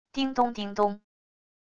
叮咚~叮咚~wav音频